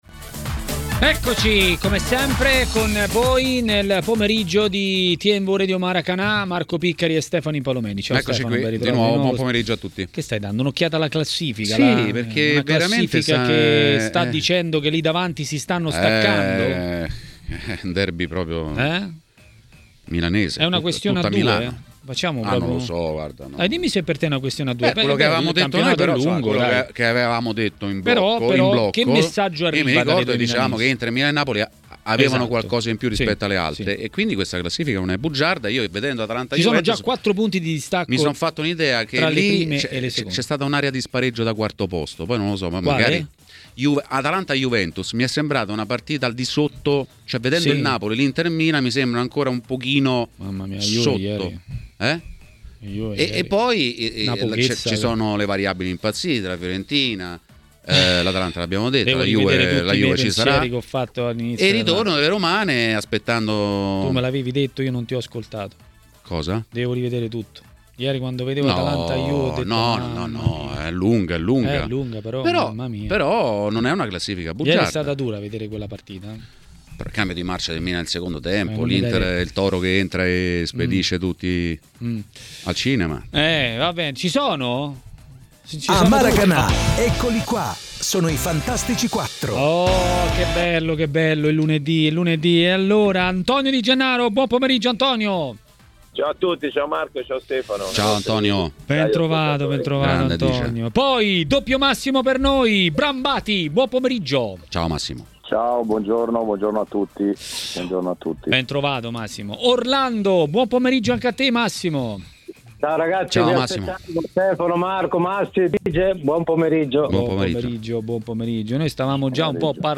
Antonio Di Gennaro ha parlato a Tmw Radio nel corso della trasmissione Maracanà: